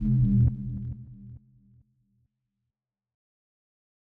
KIN Hollow Bass B.wav